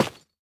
Minecraft Version Minecraft Version snapshot Latest Release | Latest Snapshot snapshot / assets / minecraft / sounds / block / tuff_bricks / place3.ogg Compare With Compare With Latest Release | Latest Snapshot